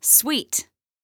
Sweet_1.wav